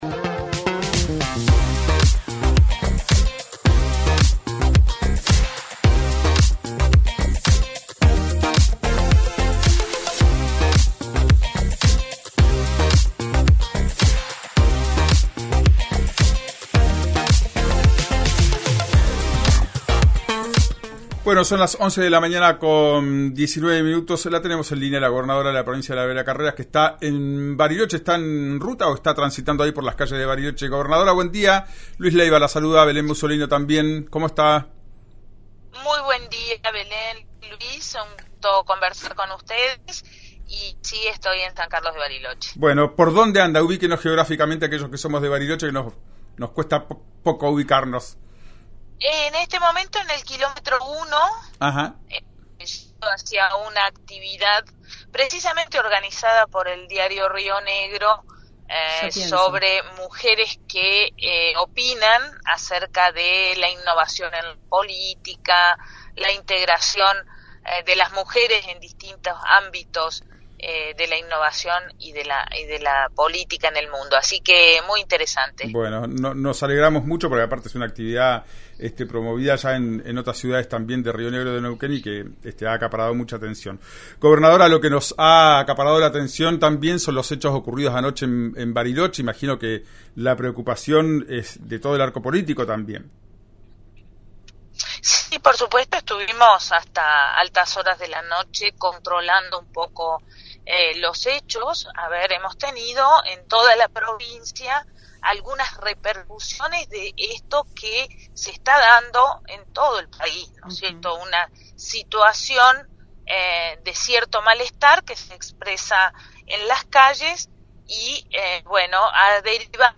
La gobernadora de Río Negro, Arabela Carreras, habló con RÍO NEGRO RADIO y analizó la situación sobre los intentos de robos organizados en la provincia e informó cuál fue la respuesta gubernamental en medio del malestar.
En una entrevista con RÍO NEGRO RADIO en el programa «Ya es Tiempo», la gobernadora de Río Negro, Arabela Carreras, abordó los recientes acontecimientos de robos organizados y los fallidos intentos que se desarrollaron en distintas zonas de la provincia. Además, explicó cuáles fueron las medidas tomadas por el gobierno para controlar la situación.